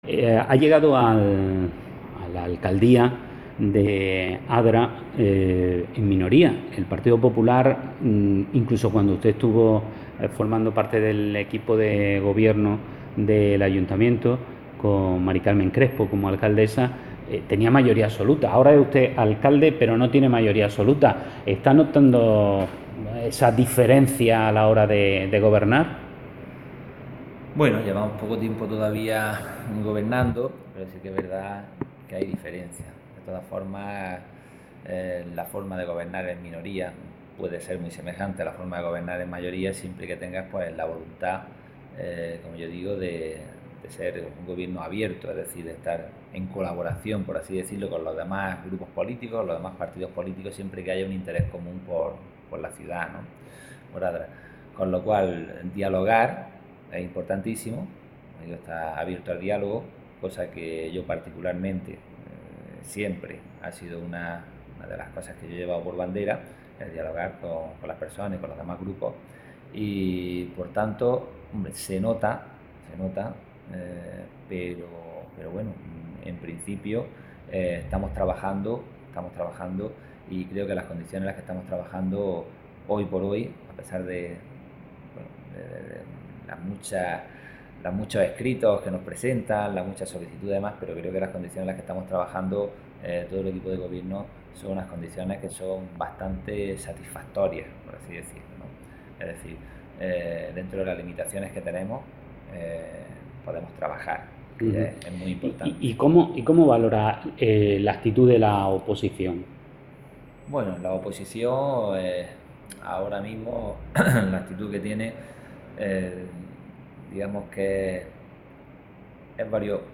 Escuche la entrevista completa Manuel Cortes llegó a la Alcaldía de Adra en las pasadas elecciones municipales tras un tiempo alejado de la política.
entrevistamanuelcortes.mp3